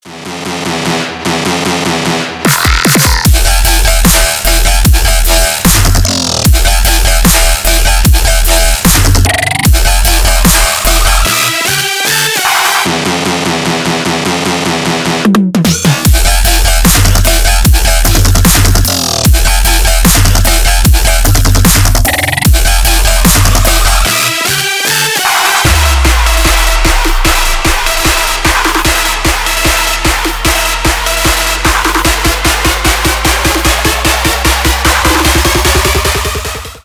Электроника
громкие